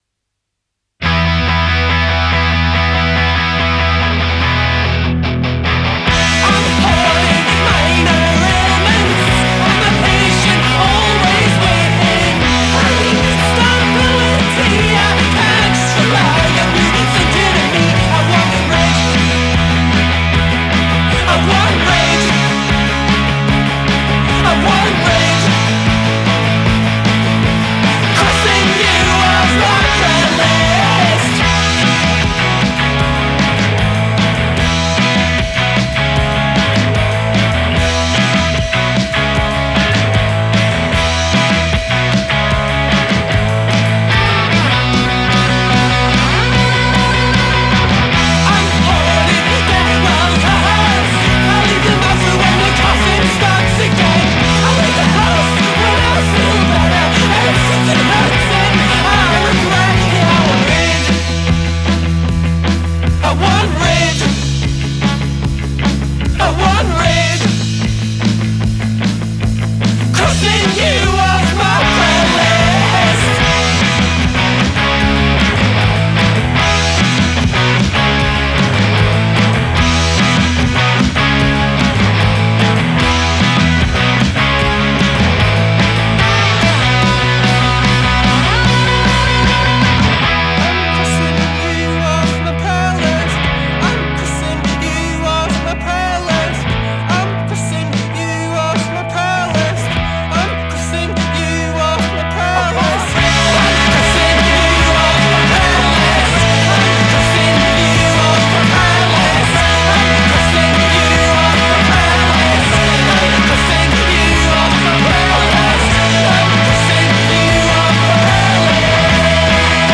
Energetic, thrilling and deliciously unpolished